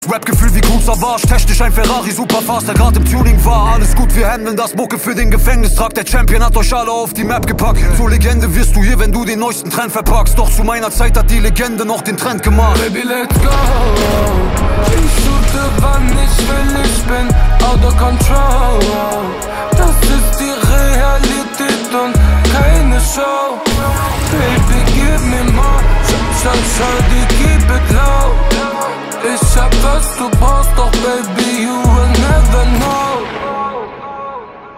Kategorien: Rap/Hip Hop